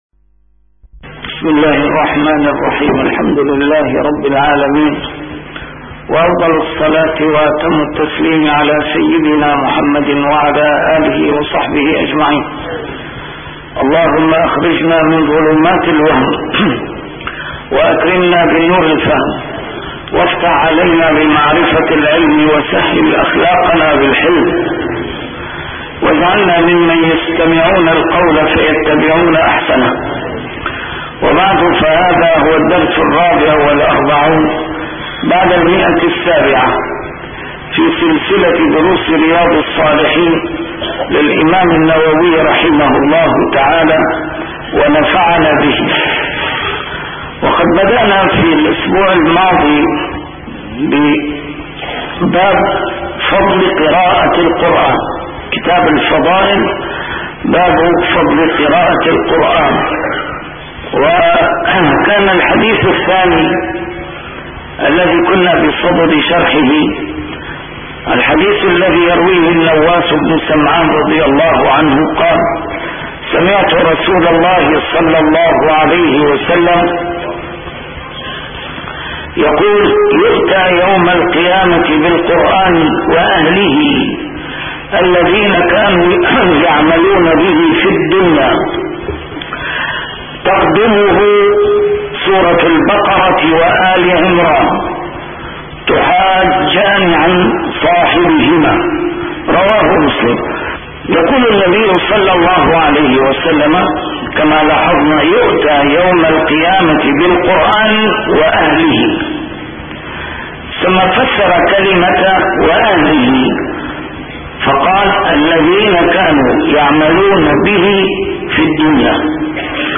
A MARTYR SCHOLAR: IMAM MUHAMMAD SAEED RAMADAN AL-BOUTI - الدروس العلمية - شرح كتاب رياض الصالحين - 744- شرح رياض الصالحين: فضل قراءة القرآن